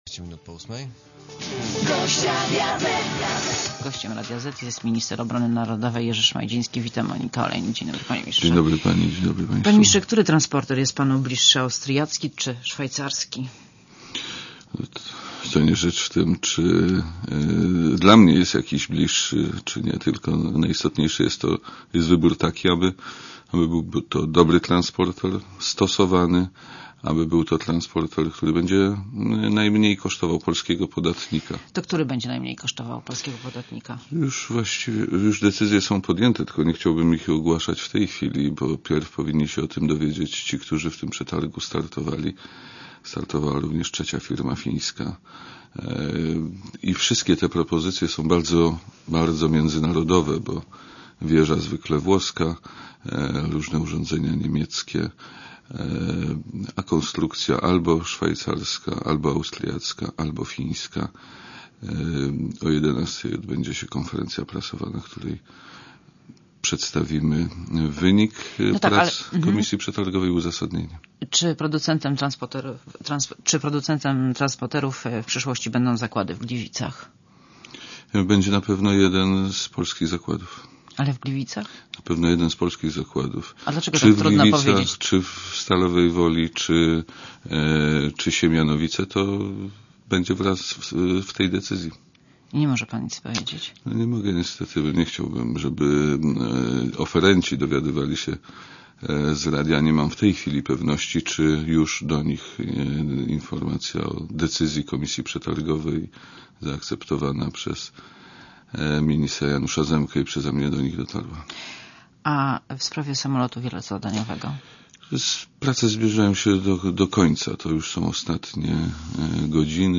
Monika Olejnik rozmawia z Jerzym Szmajdzińskim - ministrem obrony
Jerzy Szmajdziński w Radiu Zet - posłuchaj!